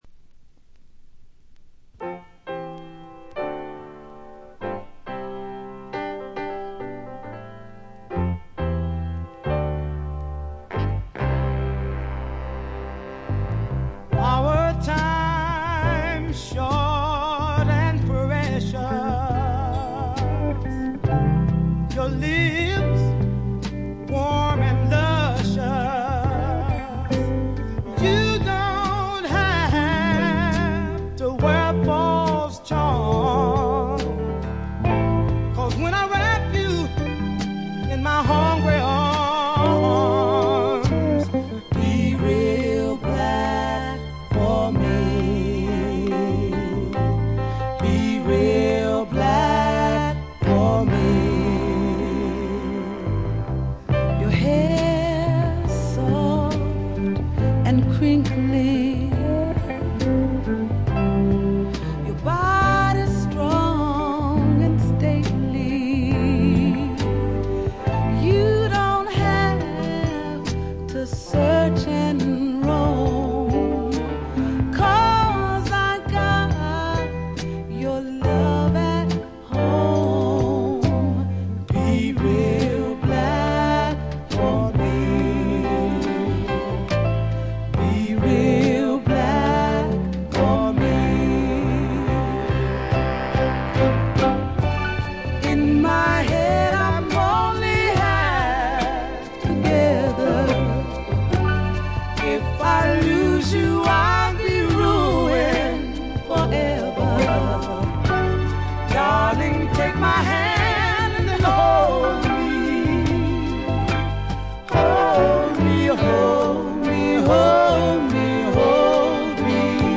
部分試聴ですが、ほとんどノイズ感無し。
頂点を極めた二人の沁みる名唱の連続で身体が持ちませんね。
試聴曲は現品からの取り込み音源です。